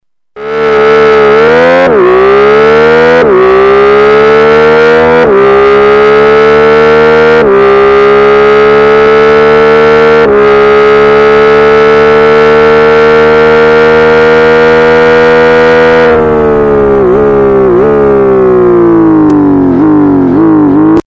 I also love my FO8's LoudV8 sound, thats amazing on my headphones.
They sound like an old 80s racing game.
However the majority of the sounds are aggressive / meaty due to the aggressive clipping.